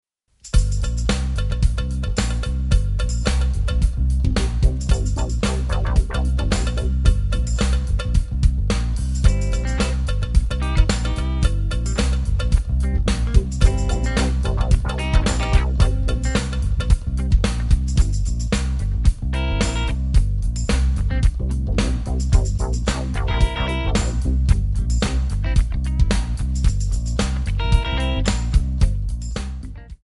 Backing track Karaoke
Pop, Rock, 1980s